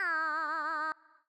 Audio / SFX / Characters / Voices / BardHare / BardHare_04.wav